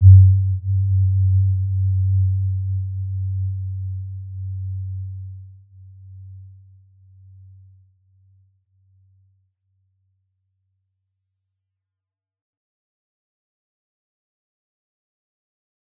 Warm-Bounce-G2-p.wav